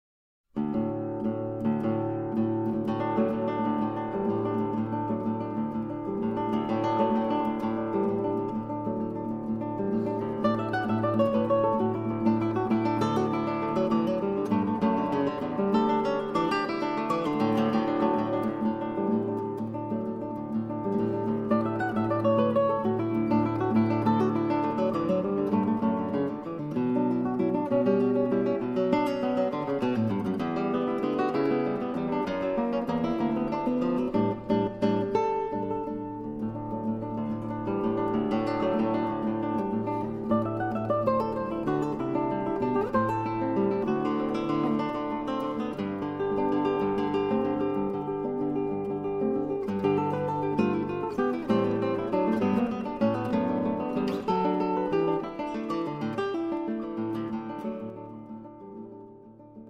SOLO GUITAR WORKS